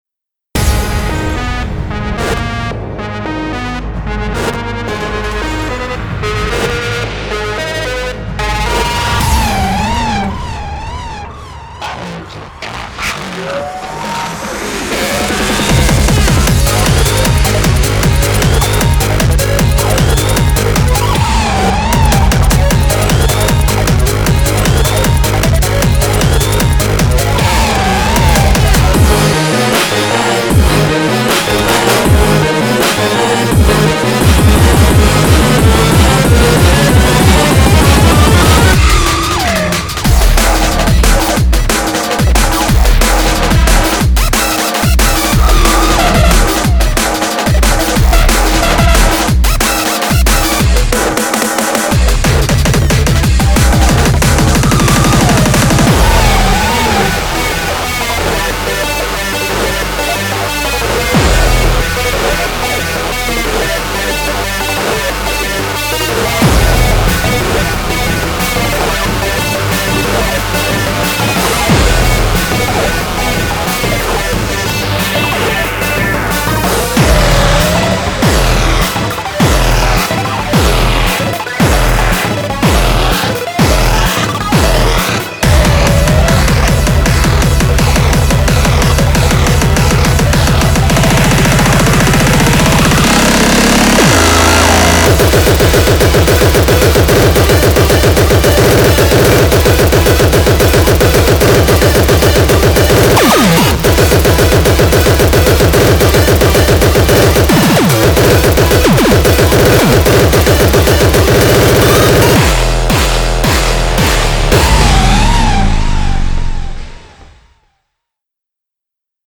BPM111-222
Audio QualityMusic Cut